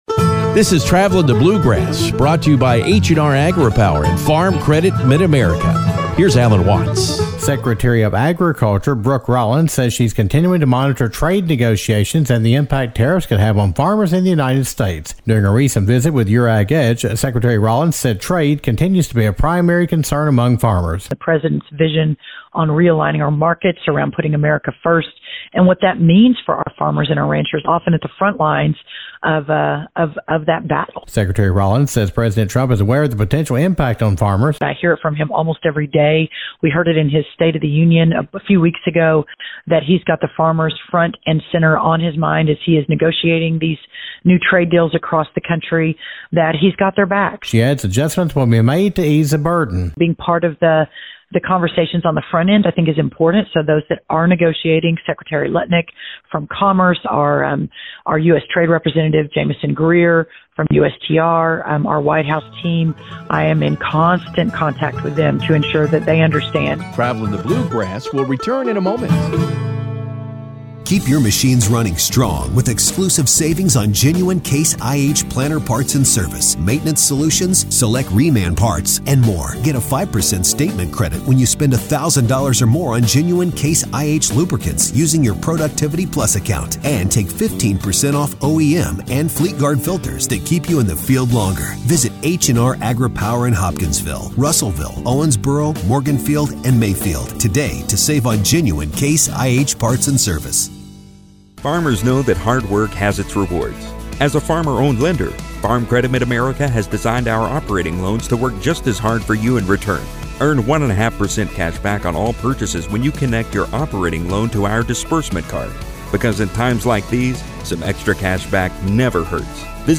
We continue our conversation with Secretary of Agriculture Brooke Rollins as we learn about the impact of tariffs on the United States farmers and progress of trade negotiations. Secretary Rollins also discusses the future of trade negotiations and possible response to help farmers impacted by tariffs.